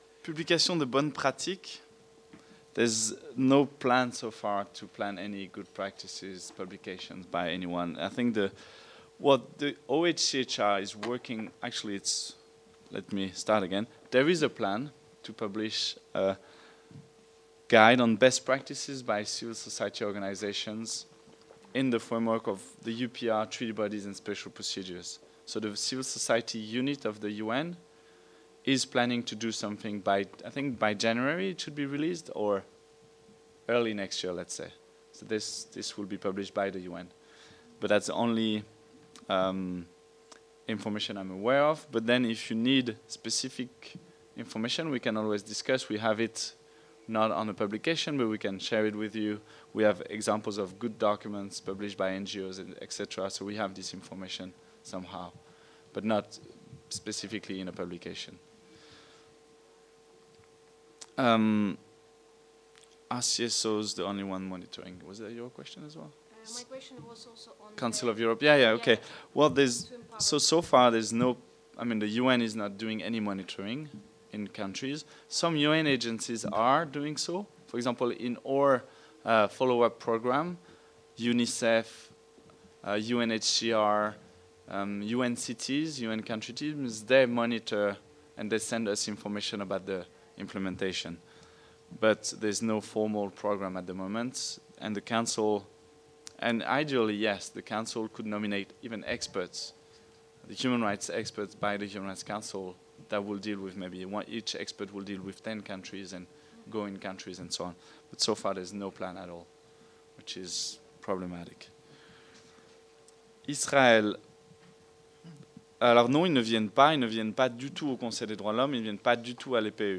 Échanges avec les participants (fr)  Partie 4 (questions) - Partie 5 (réponses) - Partie 6 (questions réponses) - Partie 7 (questions) -